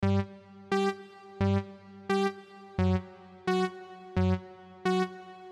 操你妈器官说唱
标签： 87 bpm Hip Hop Loops Organ Loops 950.47 KB wav Key : Unknown FL Studio
声道立体声